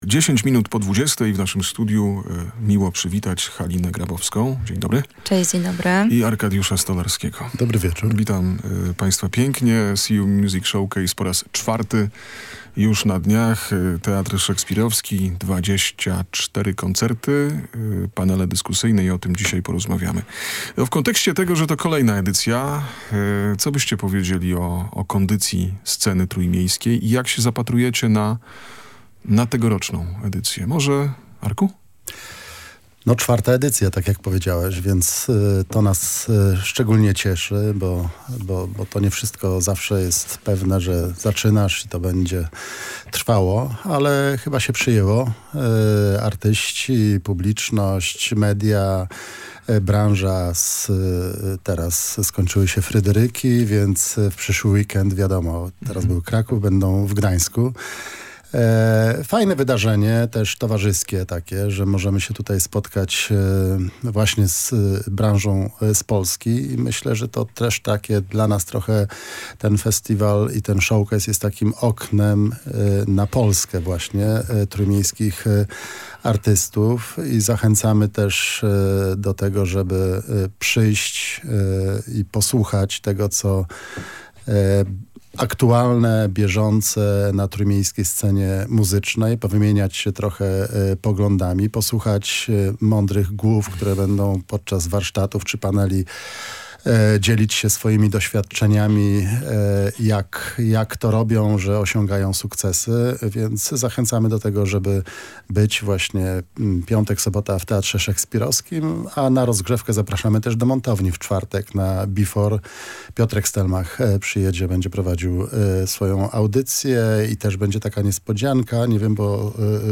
Rozpoczyna się Sea You Music Showcase. Rozmowa z organizatorami święta trójmiejskiej muzyki